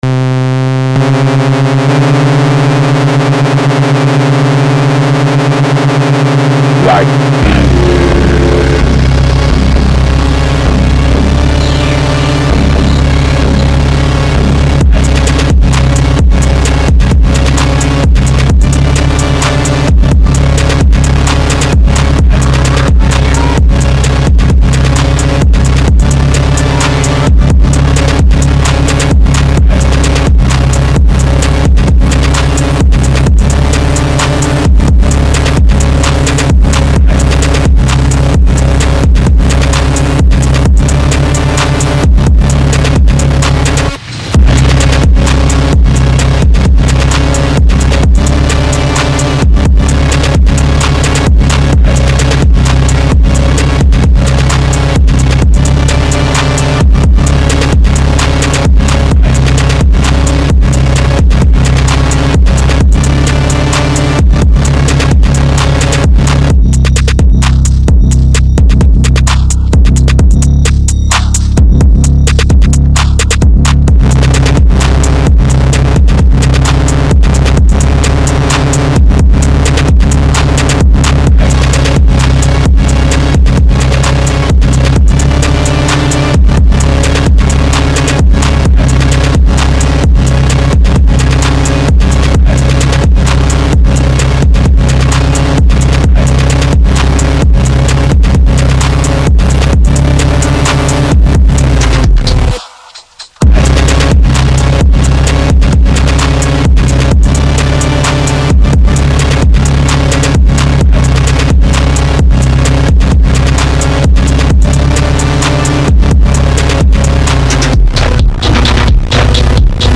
Hip-hop Агрессивный 130 BPM